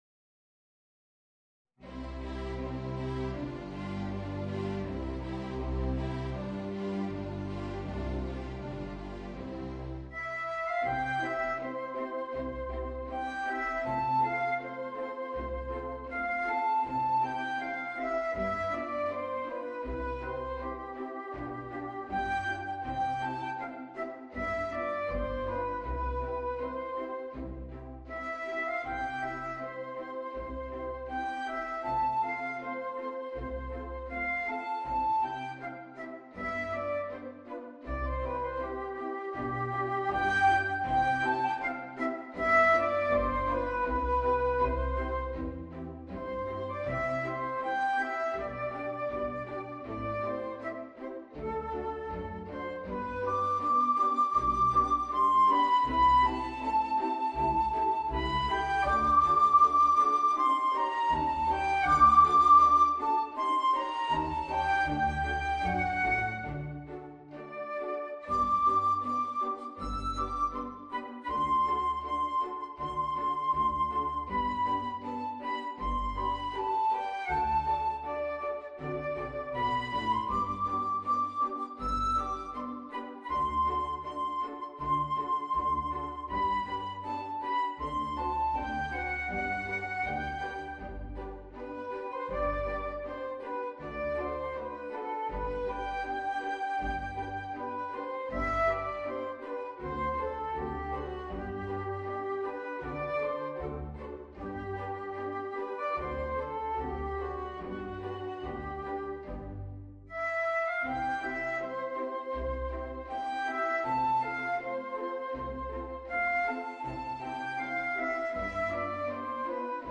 Voicing: Alto Saxophone and String Orchestra